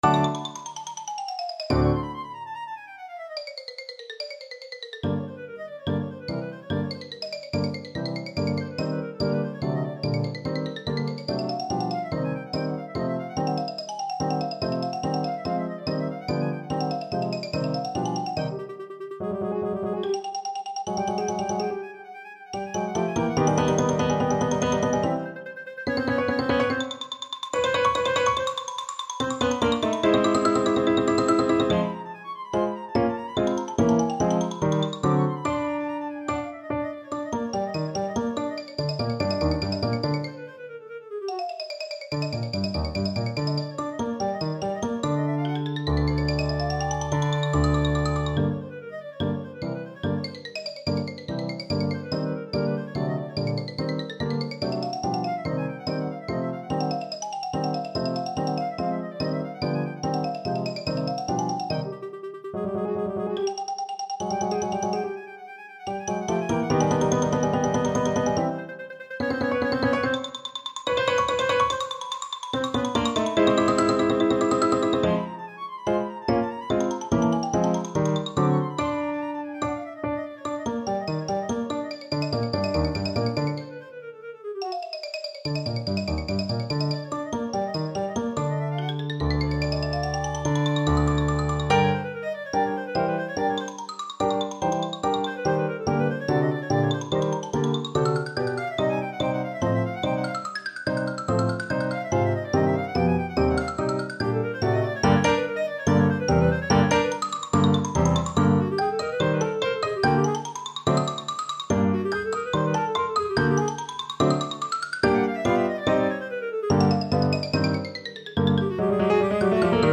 Xylophone
Clarinet
2/4 (View more 2/4 Music)
Vivace = 144 (View more music marked Vivace)